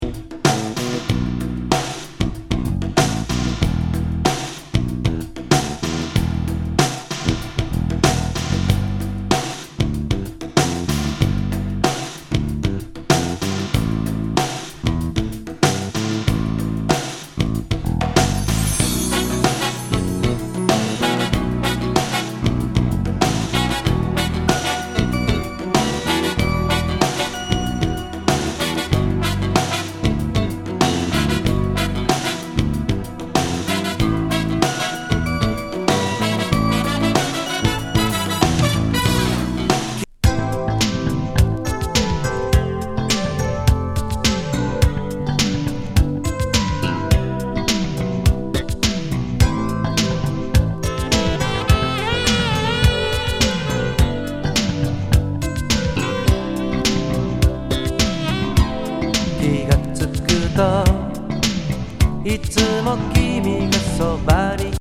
メロウ・バレアリック・アーバン・ソウル